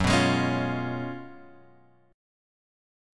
F# Major 7th Suspended 2nd Suspended 4th